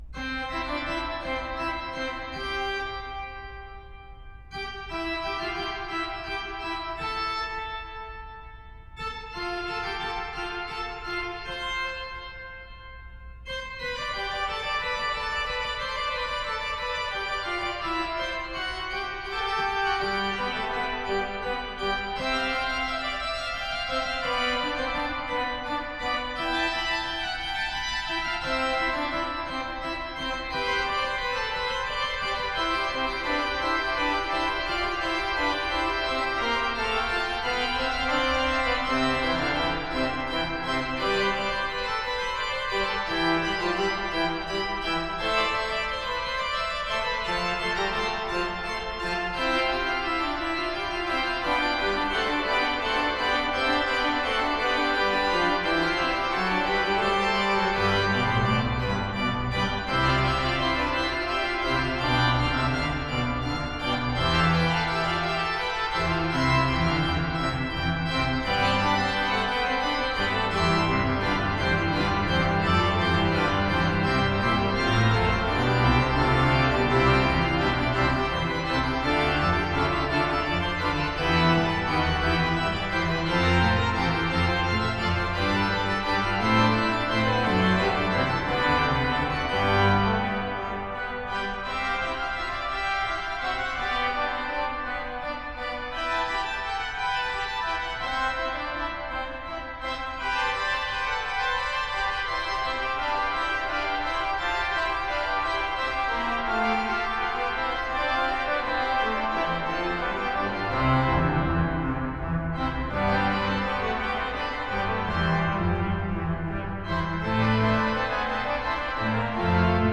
20-bwv-564-toccata-adagio-and-fugue-in-c-major-fugue.m4a